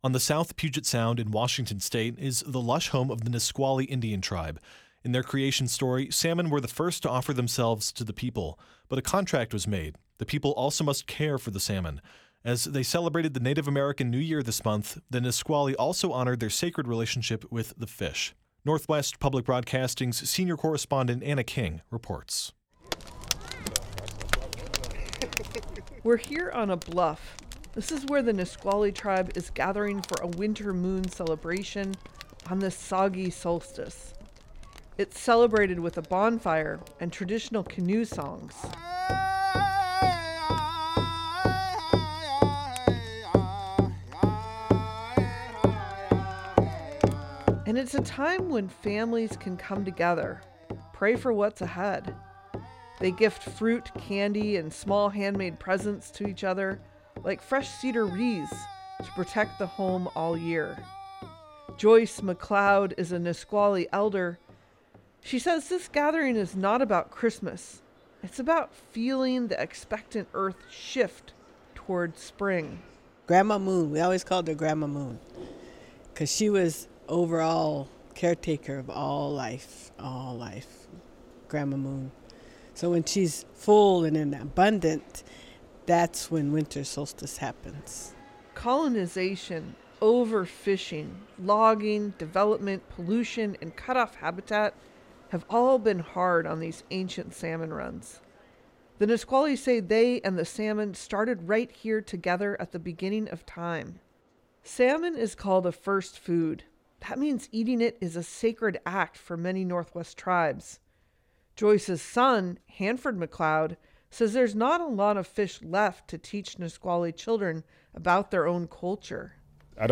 The Winter Moon Celebration is marked with a bonfire for the community and traditional canoe songs punctuated with drumming.